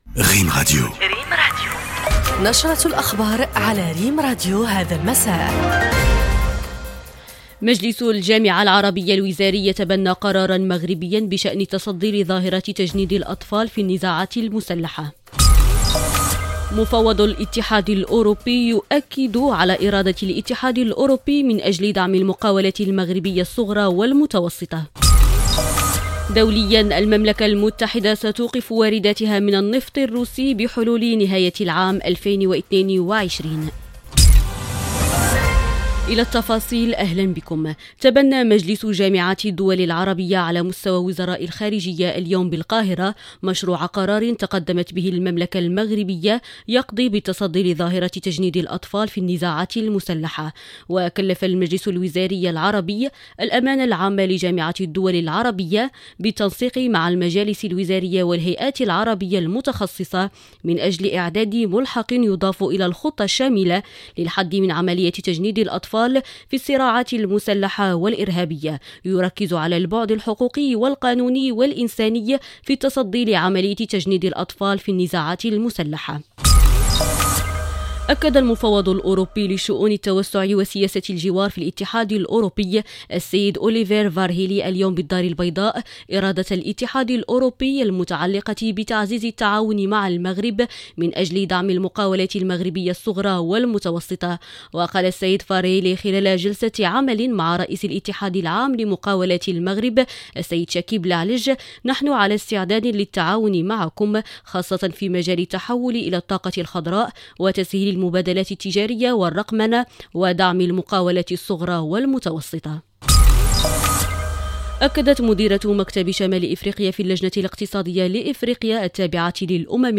Bulletins d'information